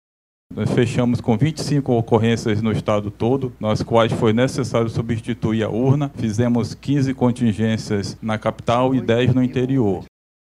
De acordo com os números divulgados, durante a coletiva de imprensa, nas primeiras cinco horas do pleito, 20 urnas apresentaram problemas, sendo que 11 precisaram ser substituídas.